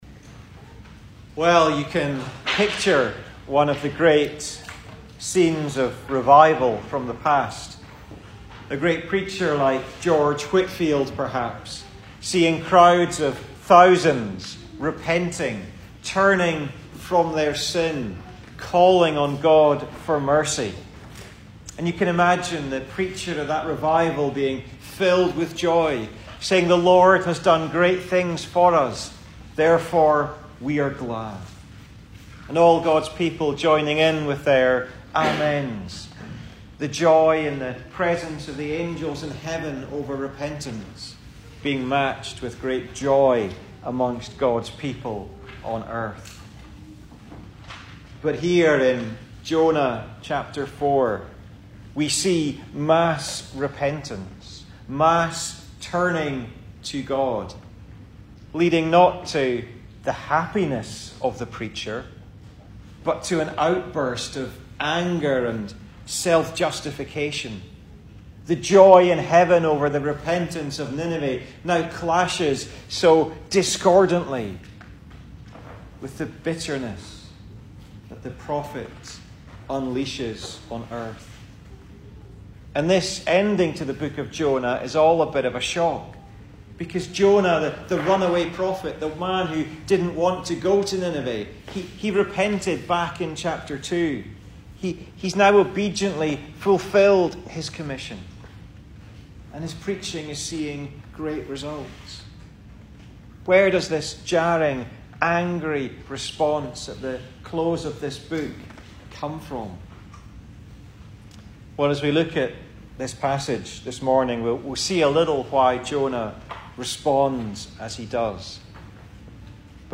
2022 Service Type: Sunday Morning Speaker